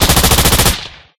Gun2.ogg